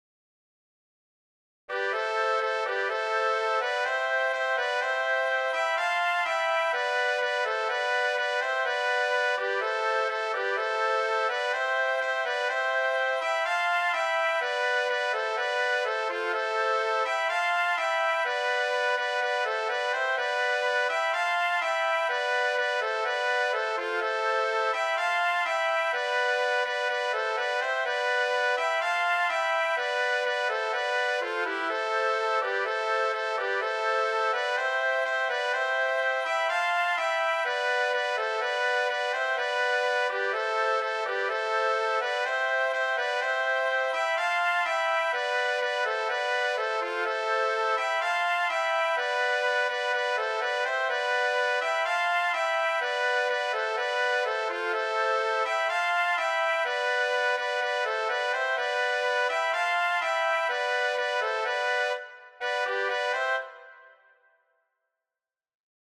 Rummad Scottish
Benvegoù 2 fleüt, oboell, treujenn-gaol, trompilh
Tonegezh La minor
Lusk 4/4
Tempo ♩=125